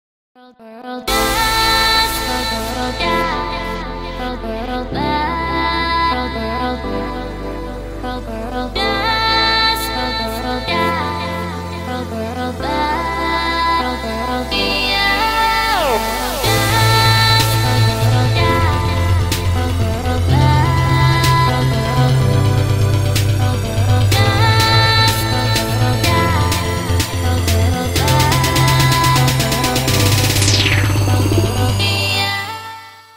• Качество: 141, Stereo
Electronic
EDM
нарастающие
progressive house
красивый женский голос
progressive trance